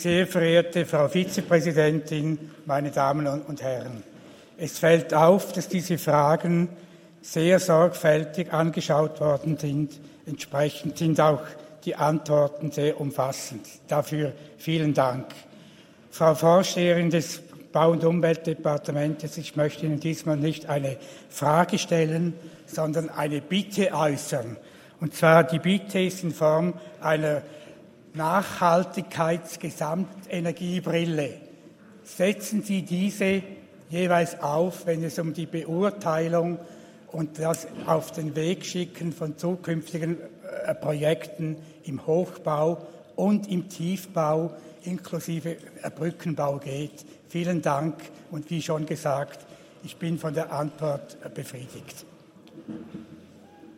20.9.2023Wortmeldung
Session des Kantonsrates vom 18. bis 20. September 2023, Herbstsession